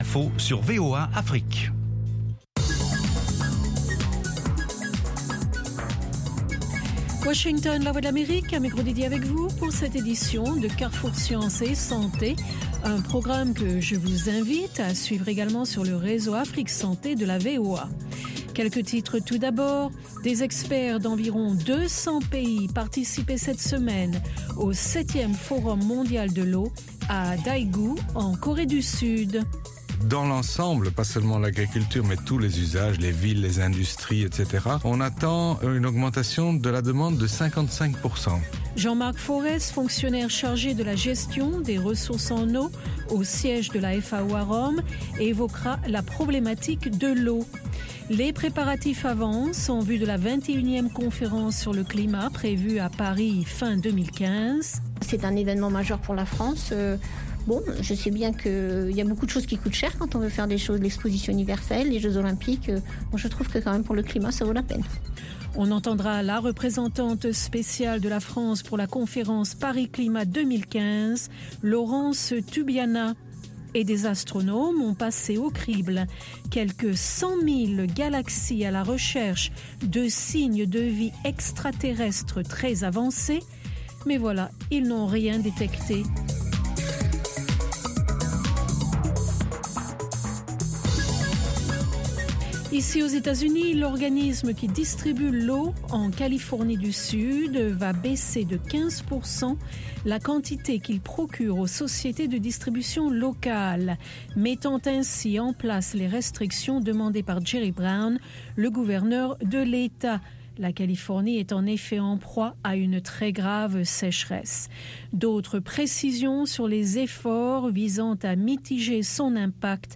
Carrefour Sciences et Santé vous offre sur la VOA les dernières découvertes en matière de technologie et de recherche médicale. Il vous propose aussi des reportages sur le terrain concernant les maladies endémiques du continent africain.